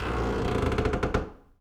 door_A_creak_02.wav